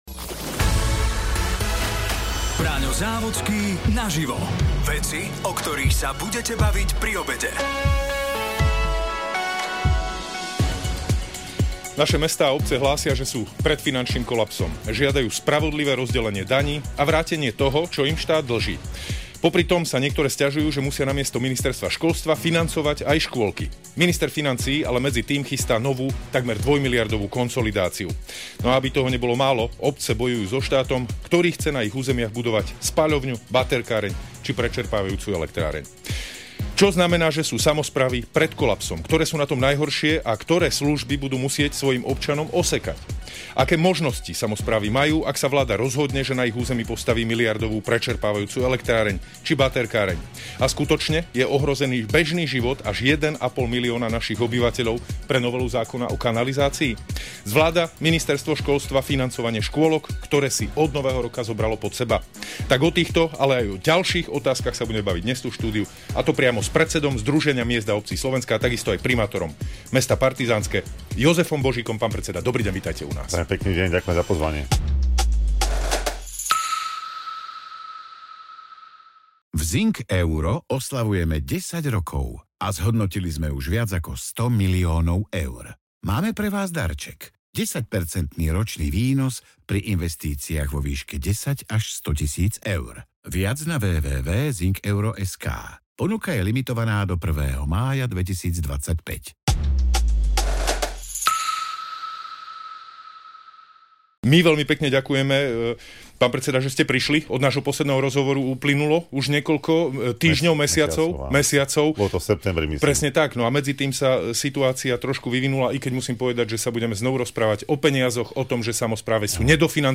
Braňo Závodský sa rozprával s predsedom Združenia miest a obcí Slovenska Jozefom Božikom.